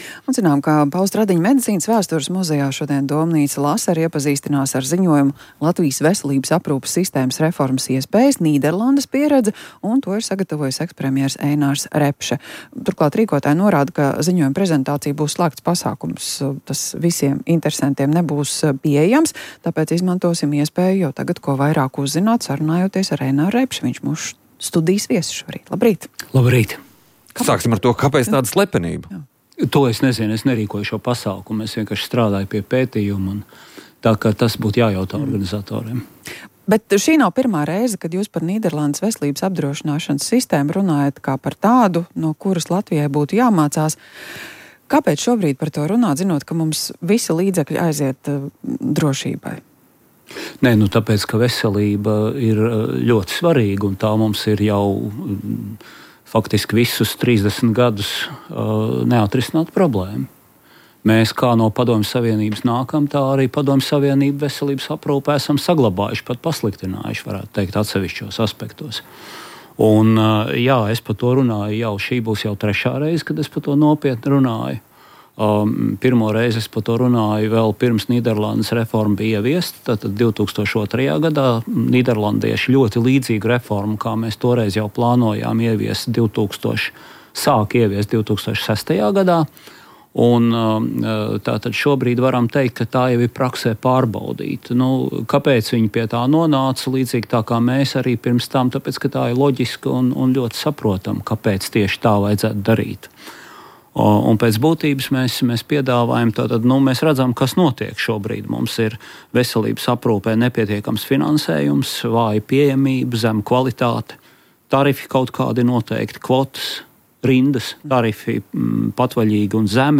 Ir objektīvs pamats, ka sabiedrībā apspriež NATO stabilitāti un vienotību, intervijā Latvijas Radio sacīja mūsu vēstnieks NATO, diplomāts Māris Riekstiņš.